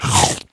bigchomp.ogg